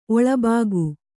♪ oḷabāgu